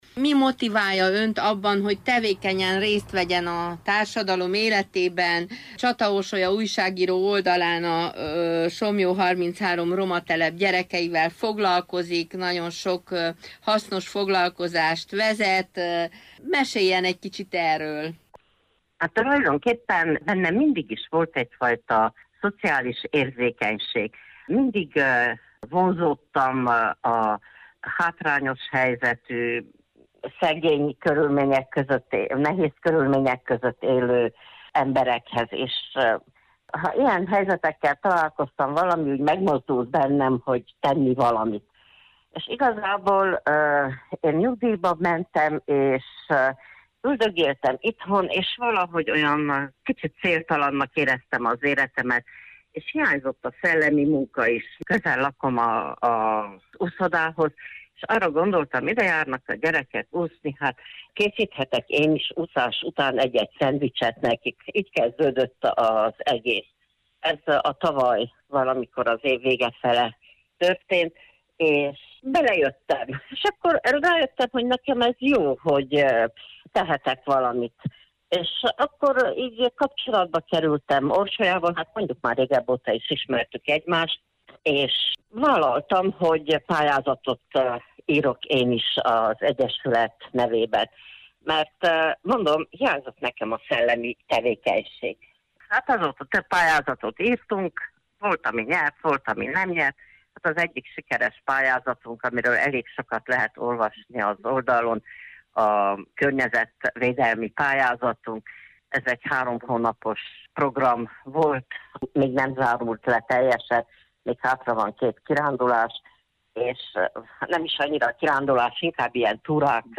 megkerestük telefonon nemrég